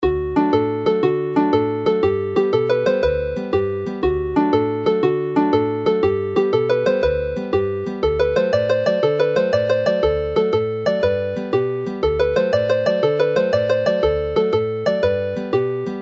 The song Y Gelynnen appears earlier in this collection in a different version; this one is a little more relaxed whereas the jaunty Sbonc Bogel (Belly jerk) which finishes the set is a lively jig.